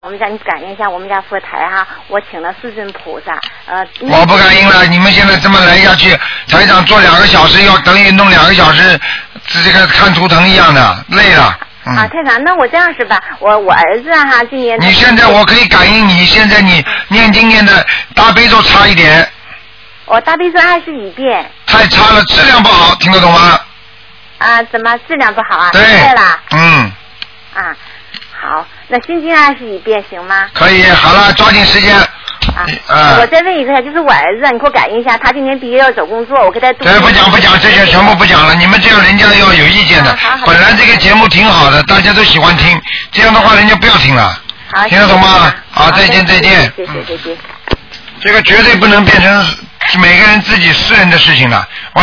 目录：2012年03月_剪辑电台节目录音集锦